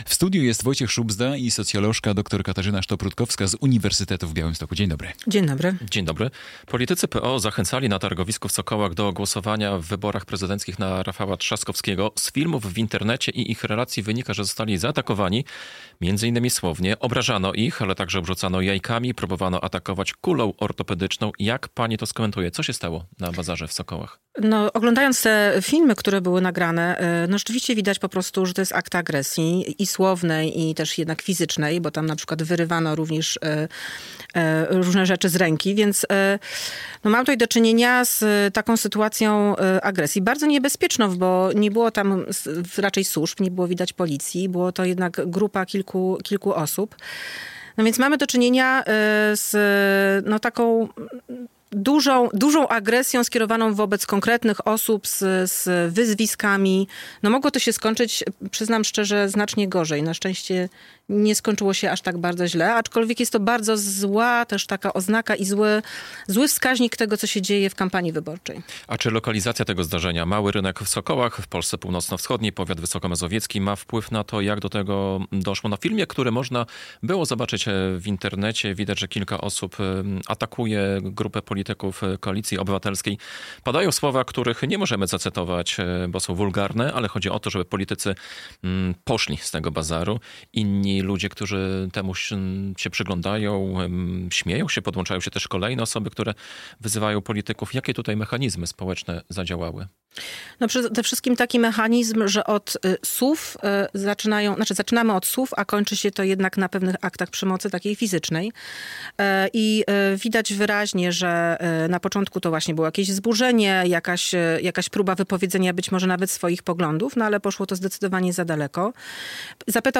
Z socjolożką